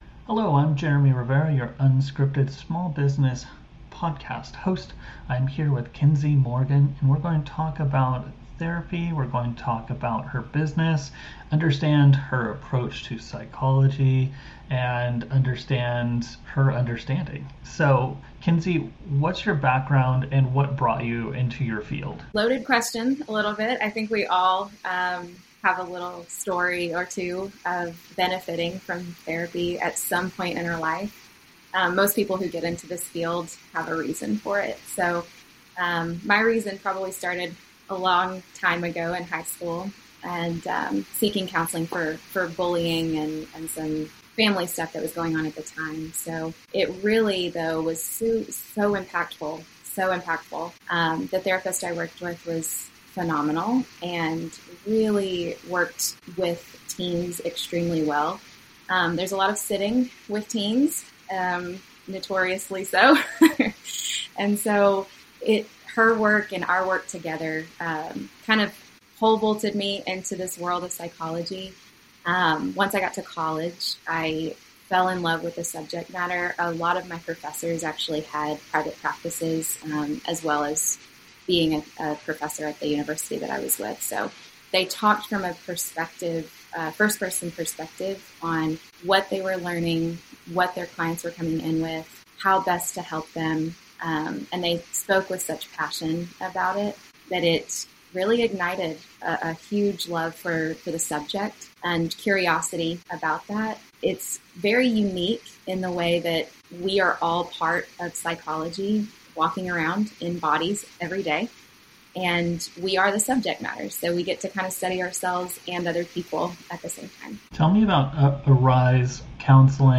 unscripted interviews small business owners, founders and creators across the United States